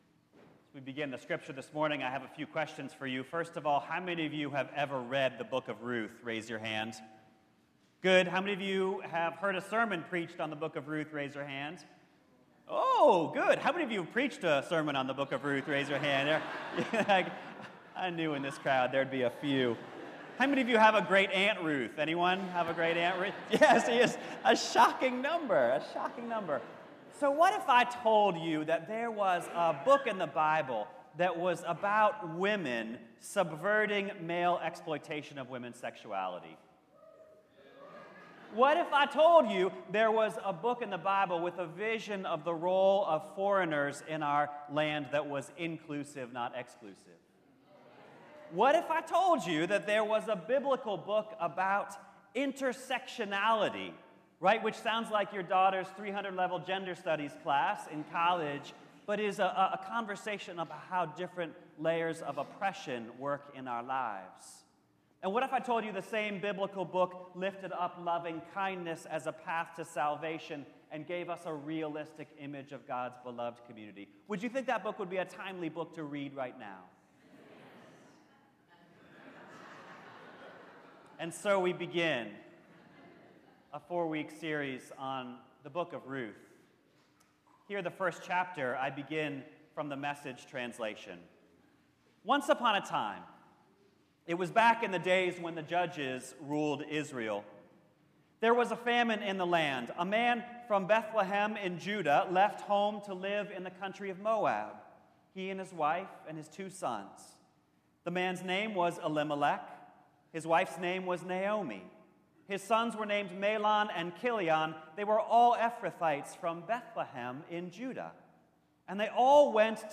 The first Sunday in a 4-week sermon series on the Book of Ruth.